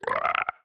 sounds / mob / strider / idle2.ogg